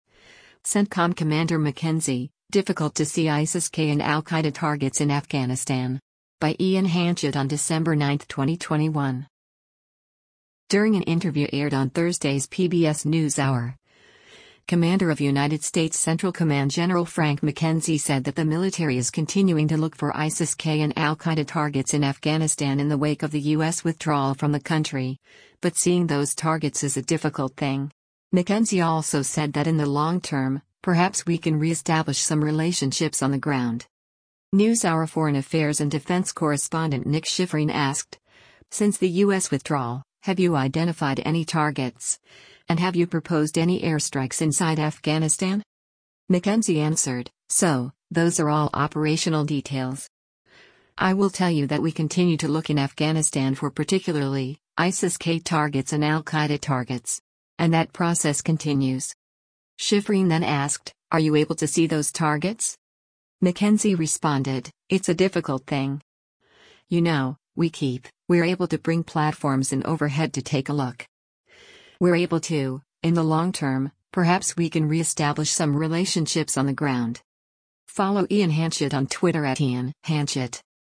During an interview aired on Thursday’s “PBS NewsHour,” Commander of United States Central Command Gen. Frank McKenzie said that the military is continuing to look for ISIS-K and al Qaeda targets in Afghanistan in the wake of the U.S. withdrawal from the country, but seeing those targets is “a difficult thing.”